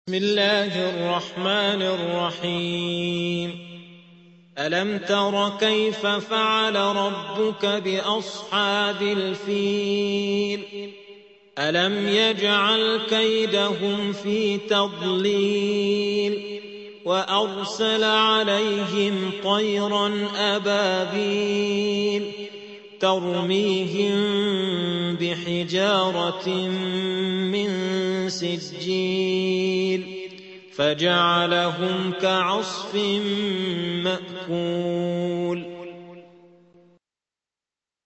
105. سورة الفيل / القارئ